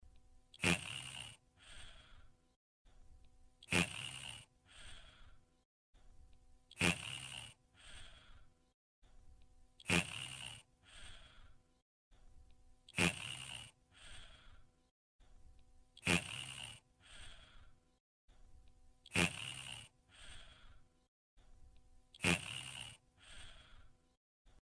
Звуки спящего человека
На этой странице собраны звуки спящего человека – от едва слышного дыхания до мягкого шевеления под одеялом.